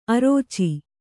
♪ arōci